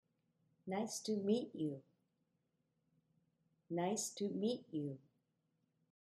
Meetが一番強調され、
toはとても軽く短く